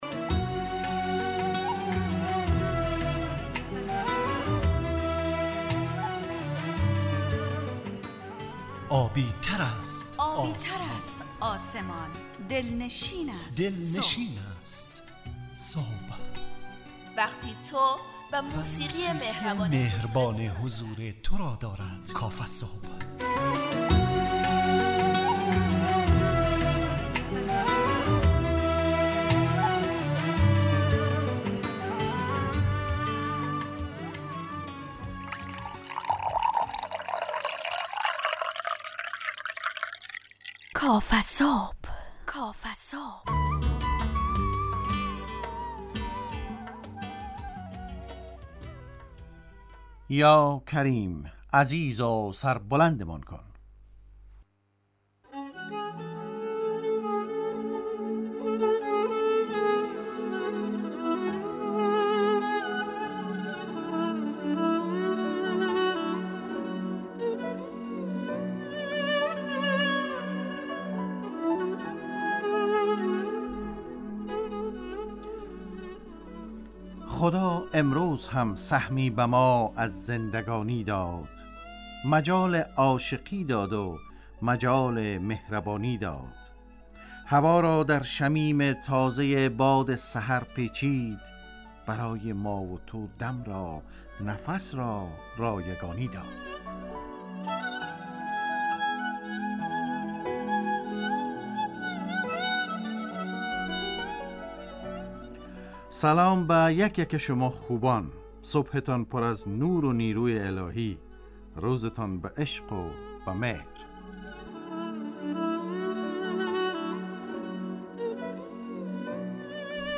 مجله ی صبحگاهی رادیو دری با هدف ایجاد فضای شاد و پرنشاط صبحگاهی